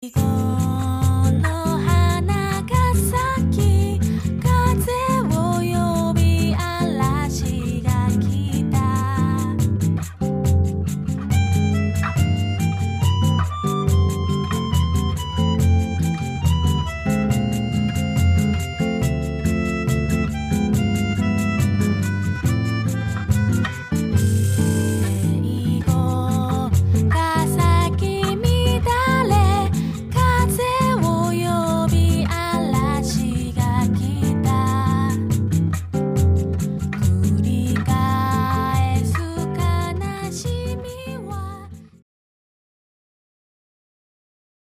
みんなの大好きな沖縄の名曲たちをボサノバで歌いました！